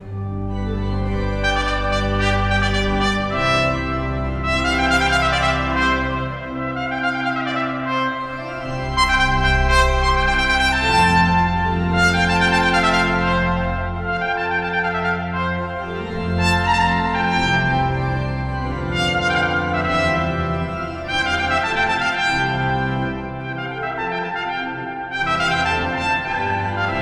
Un duo époustouflant de prestige!